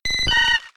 Cri de Papilusion K.O. dans Pokémon X et Y.